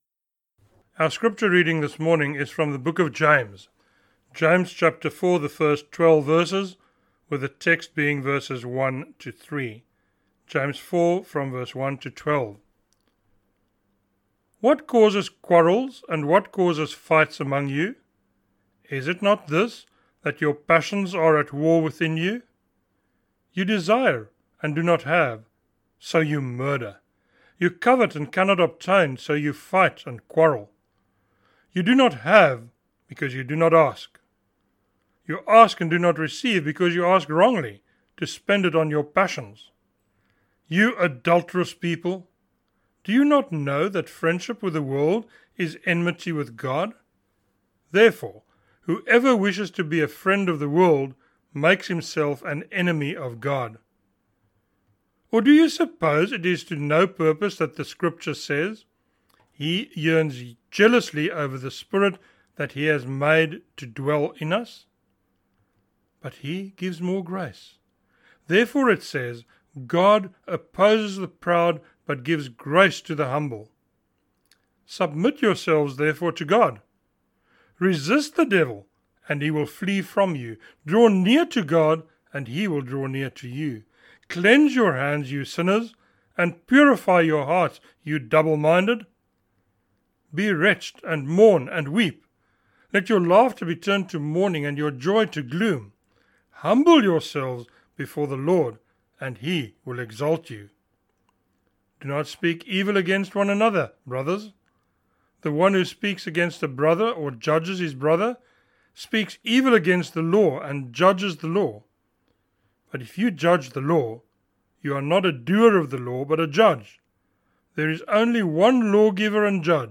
a sermon on James 4:1-3